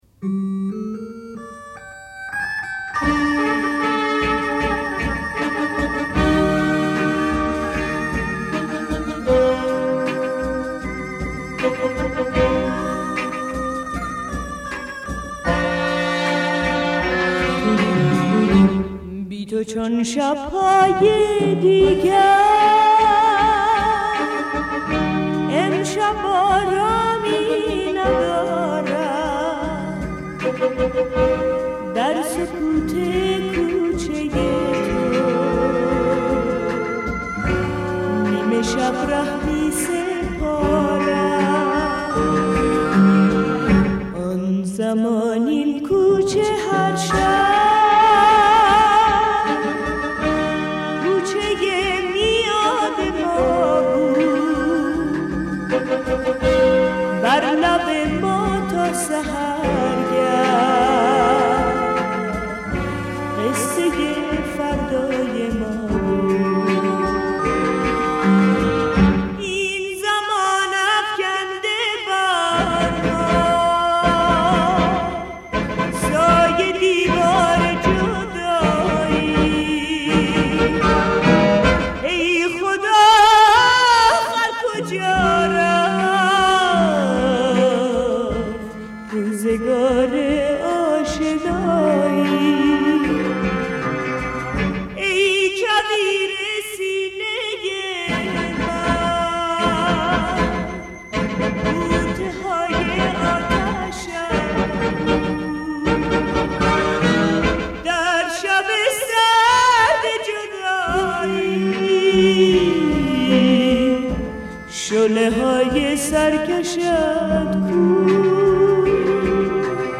غمگین و عاشقانه